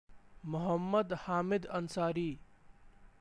File:Hamid Ansari Pronunciation.ogg - Wikipedia
Hamid_Ansari_Pronunciation.ogg